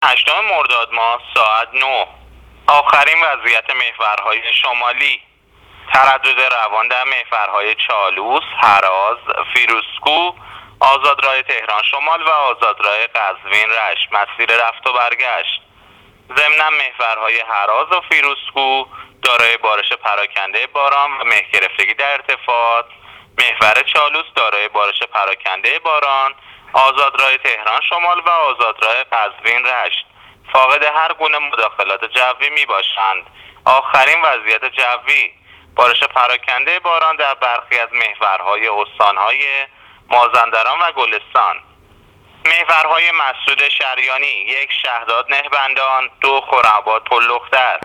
گزارش رادیو اینترنتی از آخرین وضعیت ترافیکی جاده‌ها تا ساعت ۹ هشتم مرداد